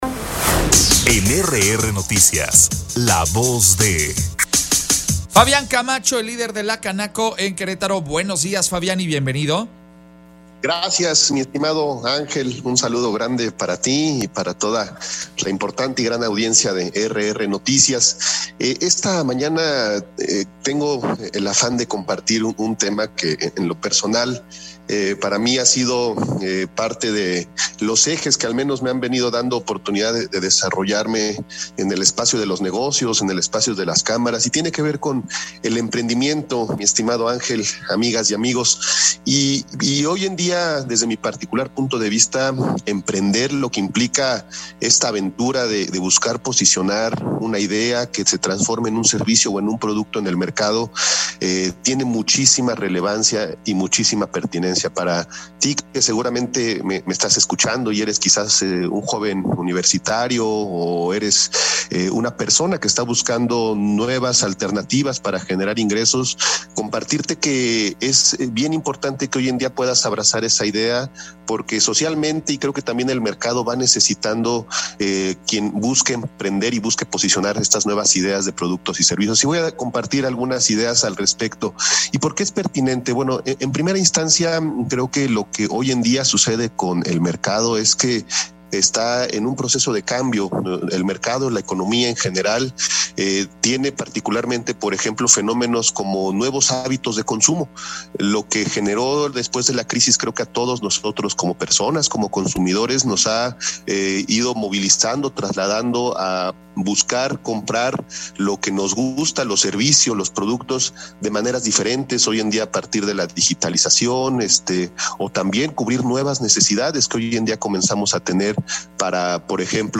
EntrevistasOpinión